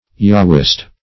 Yahwist \Yah"wist\, n.